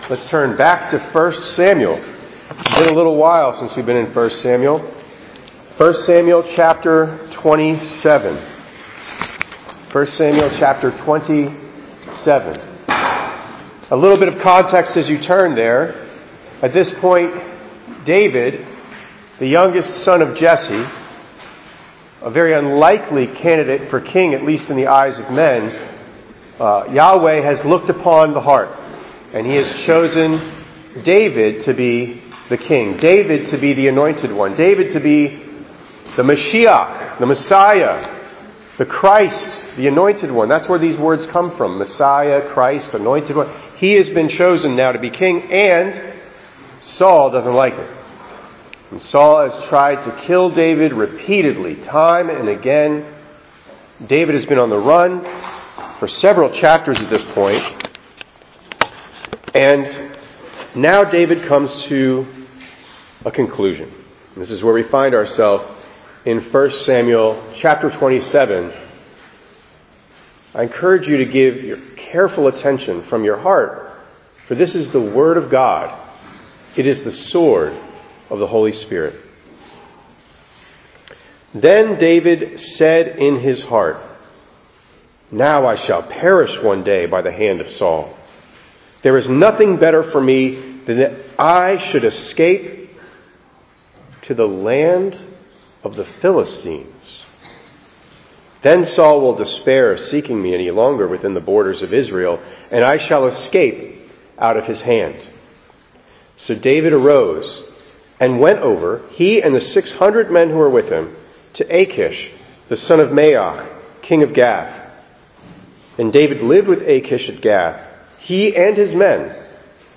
3_15_26_ENG_Sermon.mp3